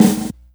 • Reverb Acoustic Snare A Key 57.wav
Royality free snare single hit tuned to the A note. Loudest frequency: 1429Hz
reverb-acoustic-snare-a-key-57-UgK.wav